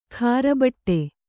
♪ khāra baṭṭe